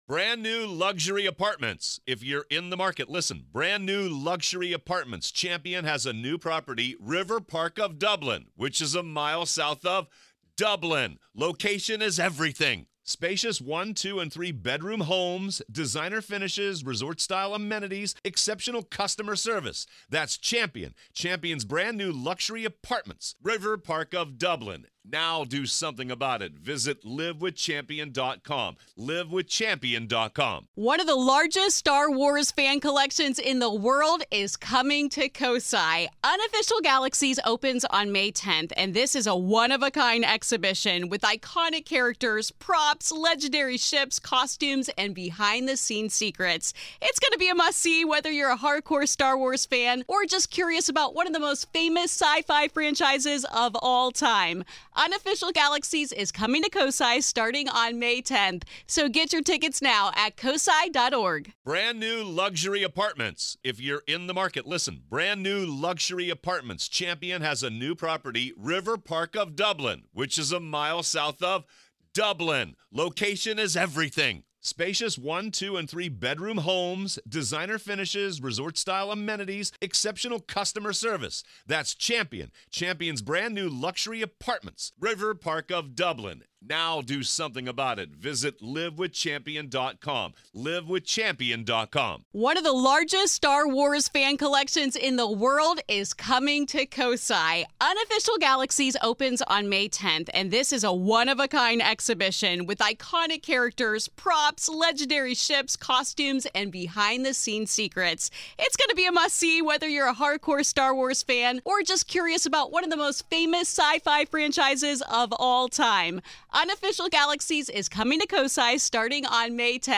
Today on the Grave Talks, a conversation about investigations, advice to novice ghost hunters, equipment, locations, and more